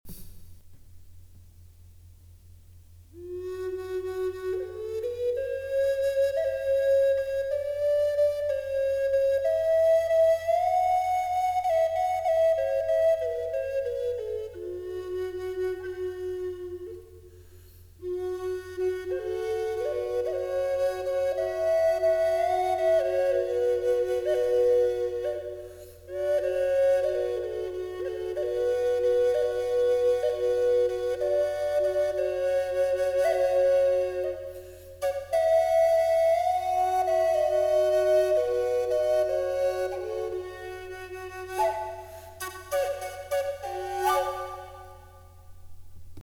Double Flûte amérindienne • Fa#
Cette flûte amérindienne double en Fa# (440 Hz) offre une expérience sonore immersive grâce à la combinaison harmonieuse d’un bourdon et d’une ligne mélodique.
Façonnée avec soin par un artisan français, cette flûte associe chêne, orme et hêtre, des essences nobles françaises qui offrent une sonorité chaude, stable et résonante.
• Note : Fa#, accordée en 440 Hz
• Essences : chêne, orme et hêtre
flute-fa-diese-double.mp3